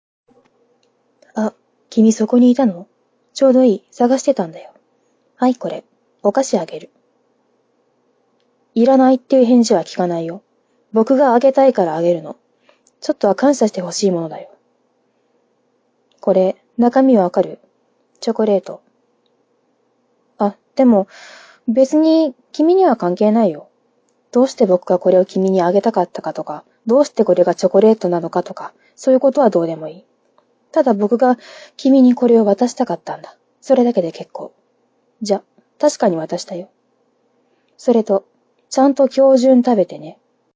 その名の通り、ボイスデータですｖｖひとり劇場です。
完璧に女王様ですね的なかんじで。